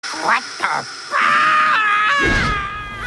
red says what the fuuuuuuuuuuuuuuu Meme Sound Effect
red says what the fuuuuuuuuuuuuuuu.mp3